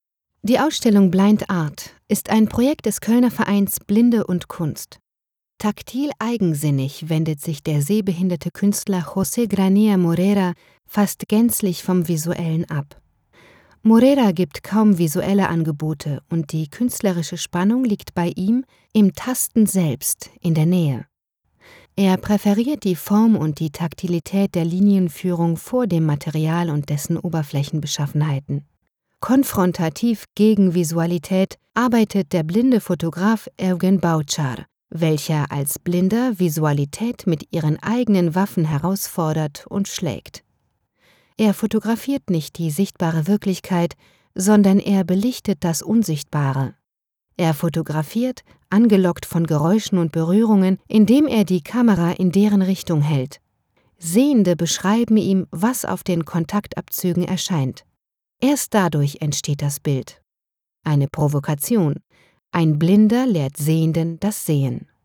Sprecherin, Übersetzerin, Medienkauffrau
DE: Audioguide – Ausstellung [Blind Art]